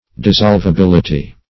Search Result for " dissolvability" : The Collaborative International Dictionary of English v.0.48: Dissolvability \Dis*solv`a*bil"i*ty\, n. Capacity of being dissolved; solubility.